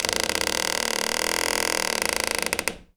door_creak_med_02.wav